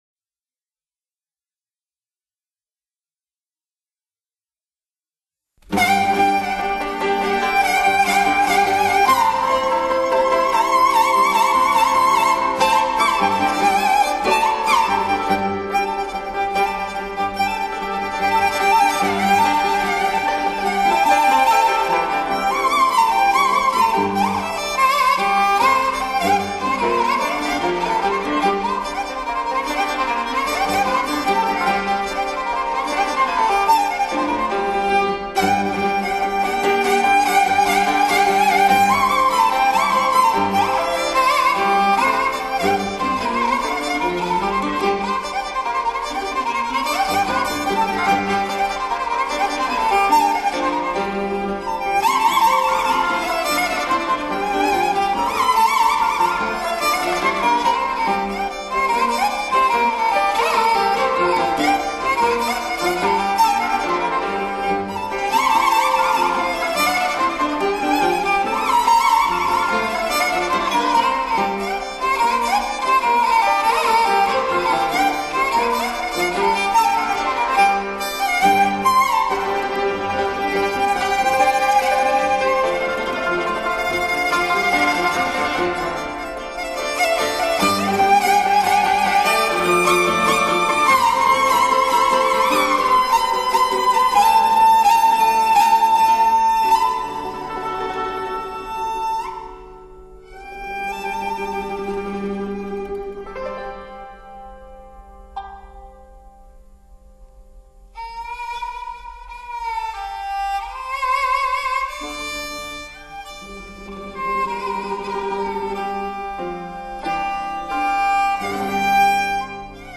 优秀的板胡曲